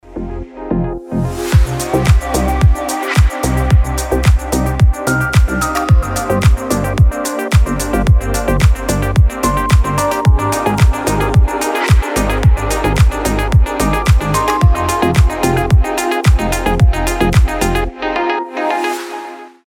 • Качество: 320, Stereo
deep house
мелодичные
без слов
Чувственная теплая музыка